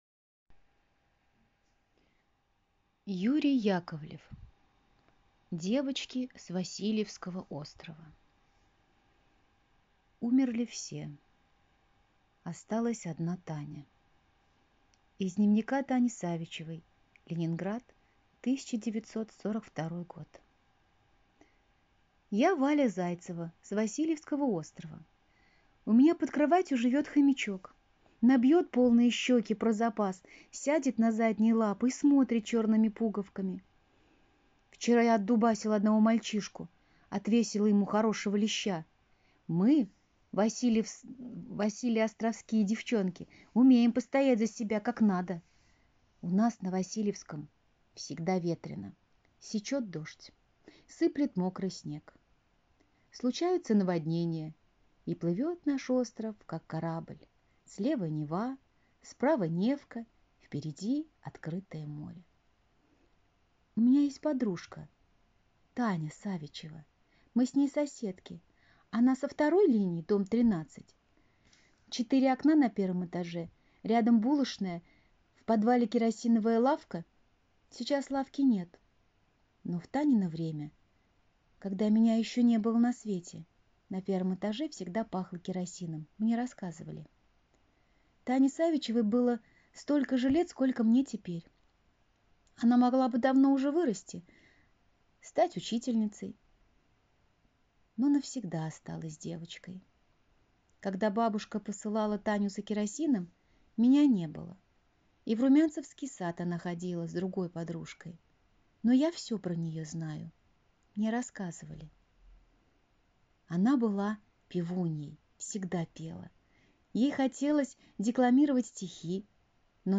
Девочки с Васильевского острова - аудио рассказ Яковлева Ю.